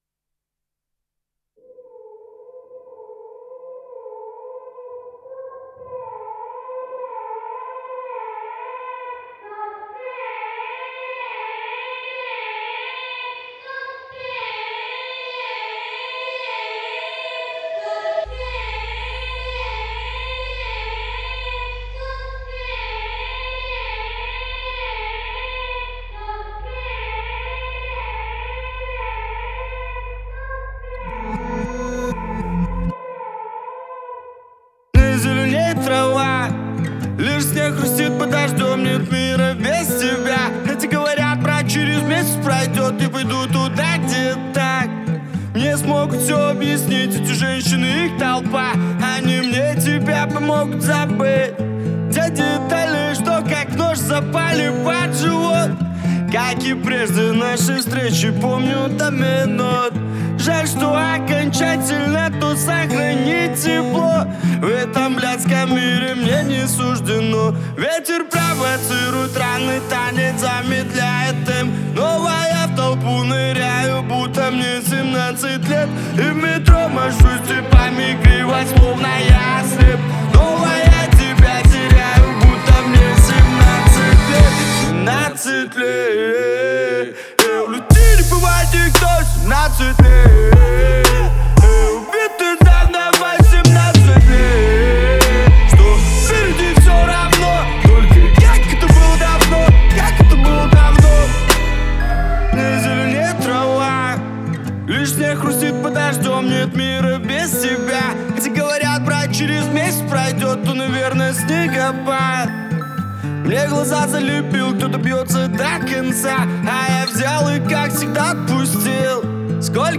поп-рэп